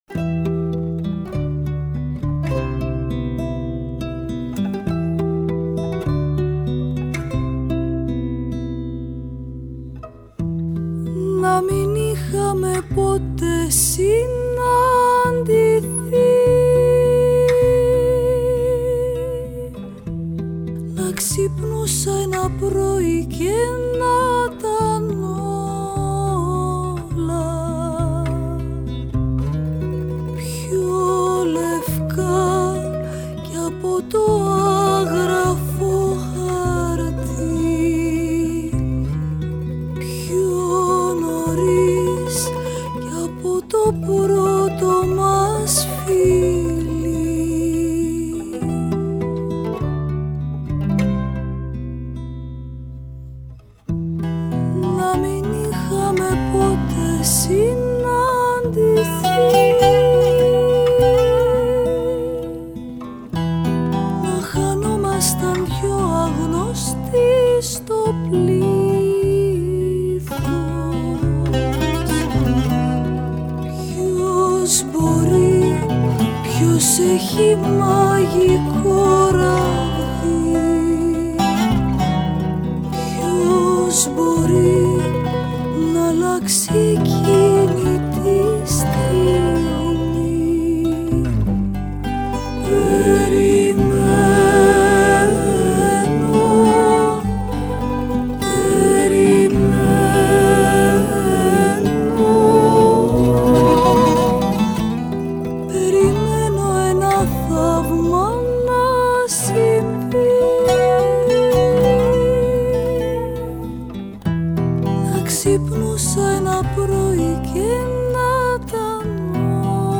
αυθεντική λαϊκή ερμηνεύτρια